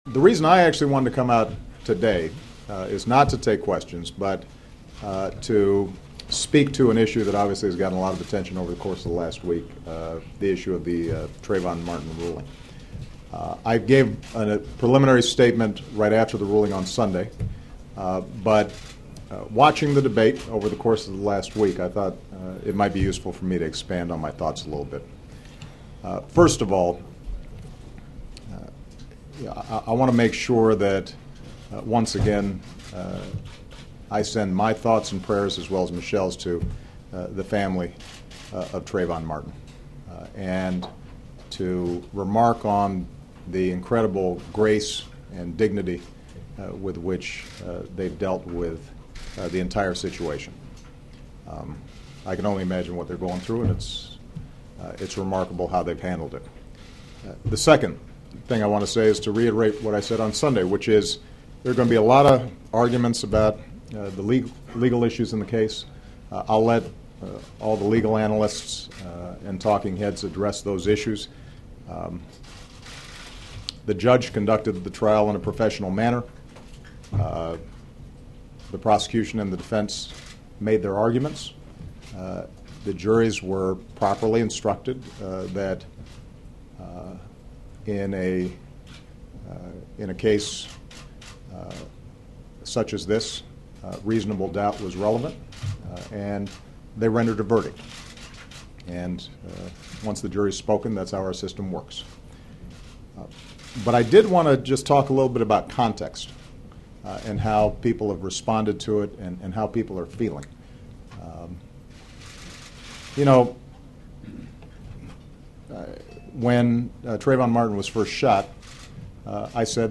President Obama's statement Friday in the White House briefing room, where he made an unscheduled appearance and talked about the Trayvon Martin shooting death and last week's acquittal of George Zimmerman.